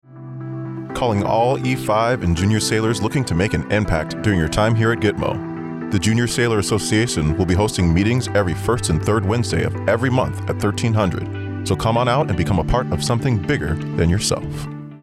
A radio spot informing Naval Station Guantanamo Bay residents of the Junior Sailor Association.